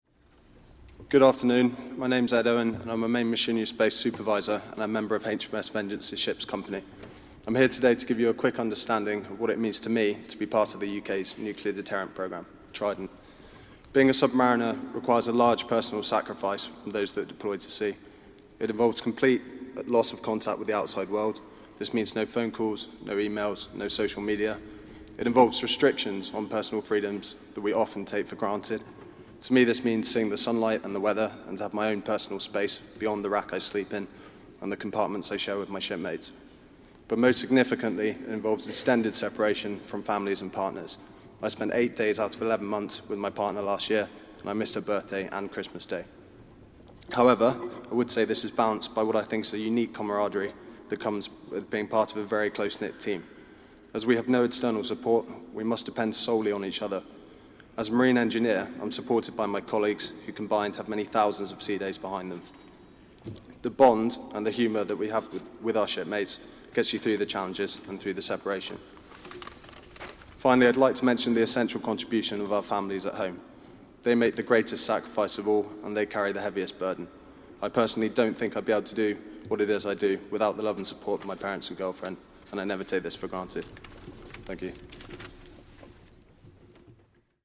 gives a Testimony at a service to recognise fifty years of Continuous At Sea Deterrent at Westminster Abbey at Noon on Friday 3rd May 2019.